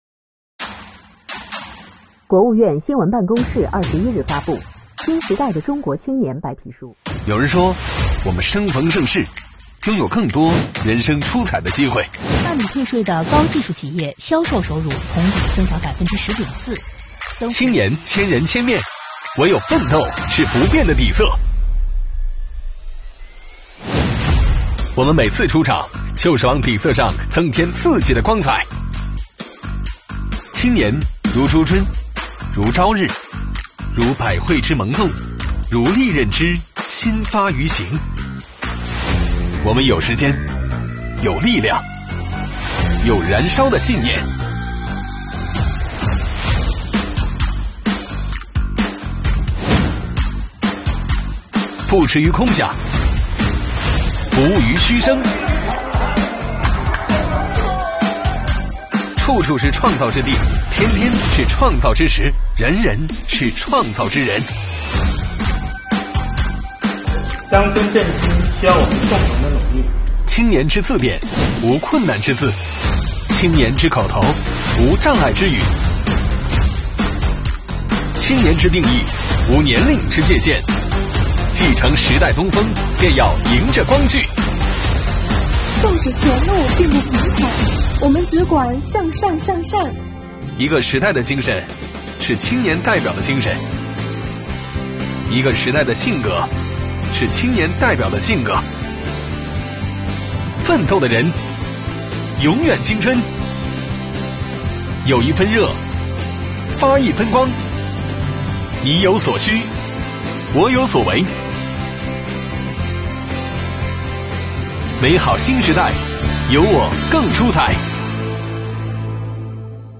作品取材于税务人的日常工作，通过探索者们的铿锵话语快速串联起多样景别，整体节奏活泼明快，传递青春正能量。作品展现了新时代中国青年勇挑重担、向上向善，将个人奋斗“小目标”融入党和国家事业“大蓝图”的模样。